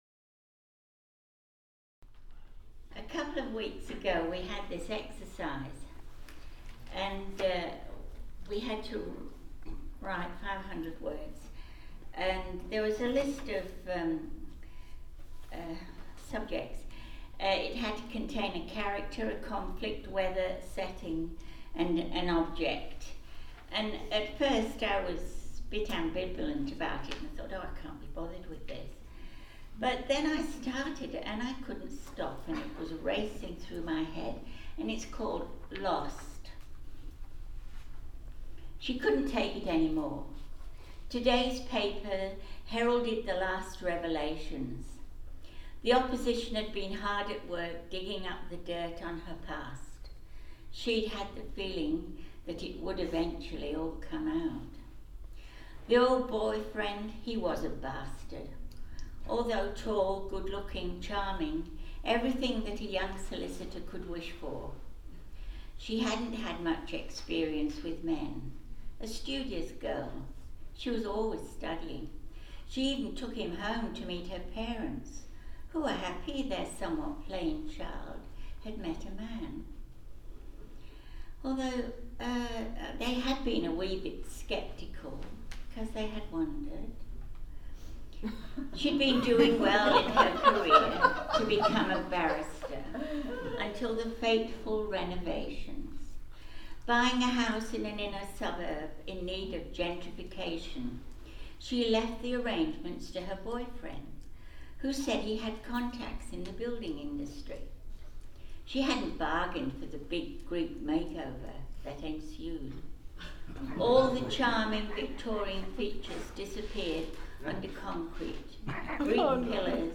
It took place recently at the Mornington Librarys quarterly ‘Live’nLocal’ readings session. To fully appreciate the humour you’d need to be Australian … or have some understanding of the current political scene in OZ.